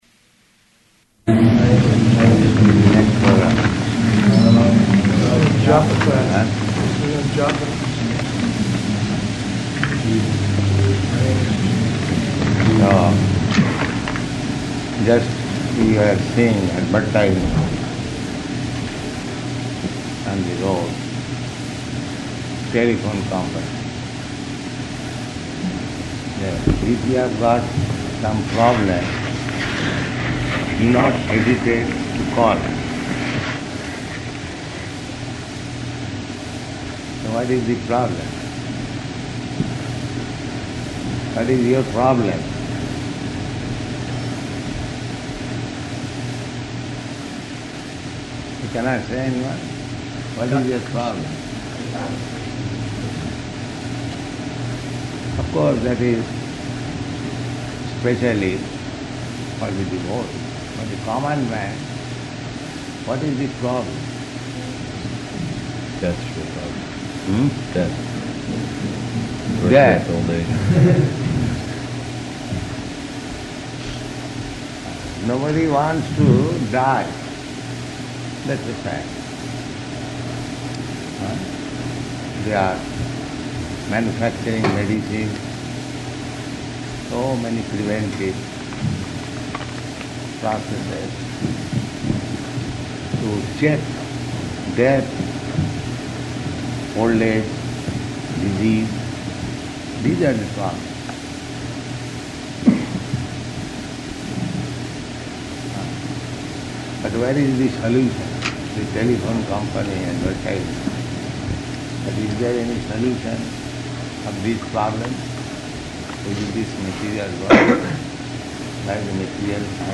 Room Conversation
Room Conversation --:-- --:-- Type: Conversation Dated: June 14th 1975 Location: Honolulu Audio file: 750614R1.HON.mp3 Devotee: Japa time [chanting in background] Prabhupāda: Eh?